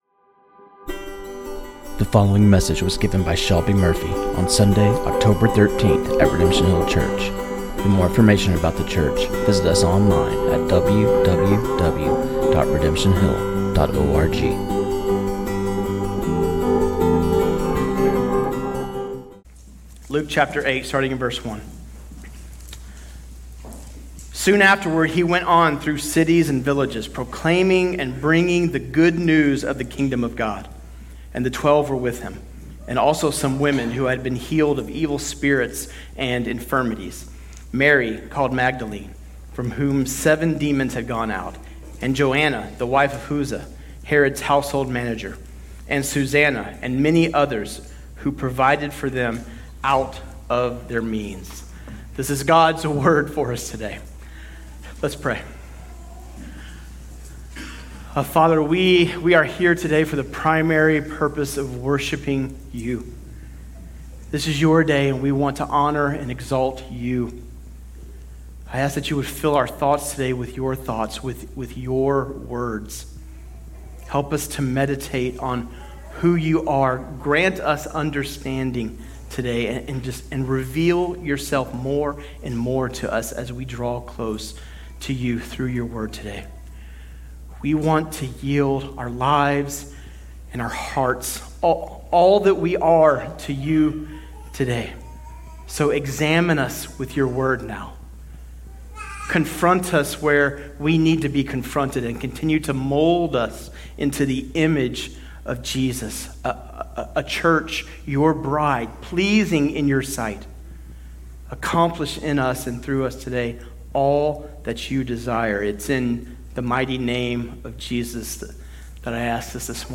This sermon on Luke 8:1-3